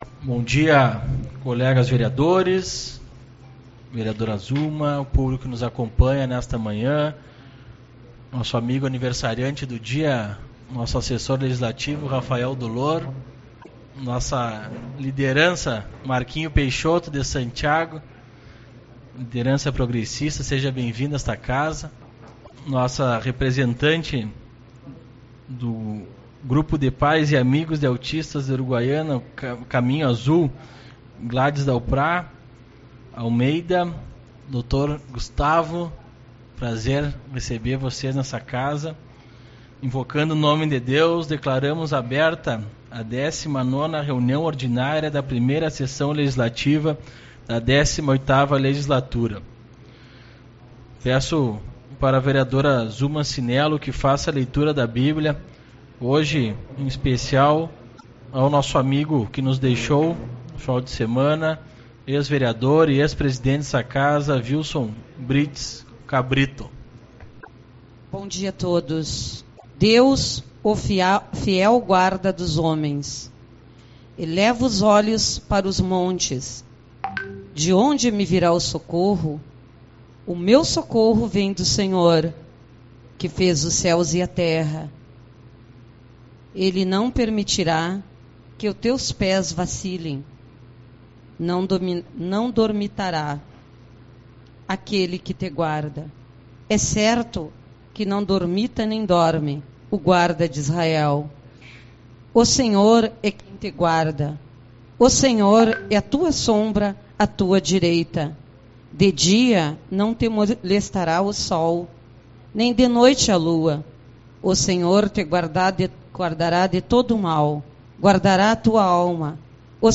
06/04 - Reunião Ordinária